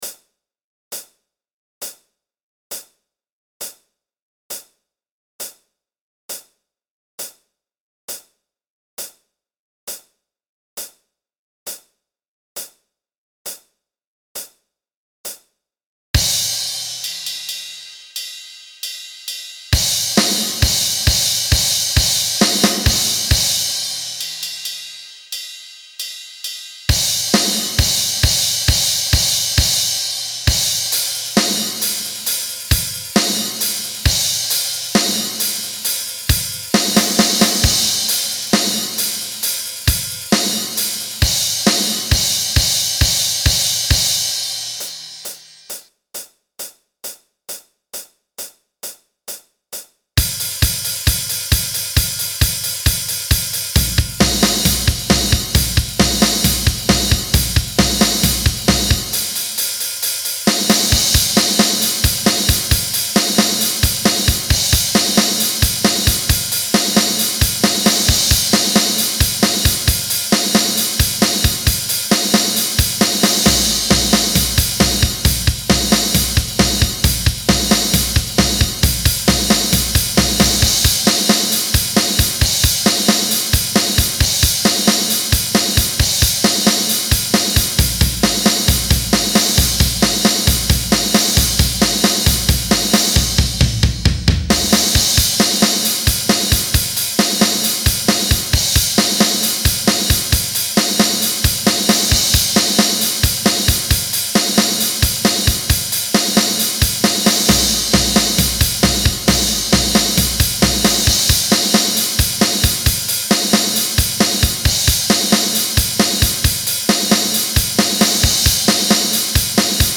Drums only - create what you want
*TIP:  The song begins with a 2 count on the hi-hat.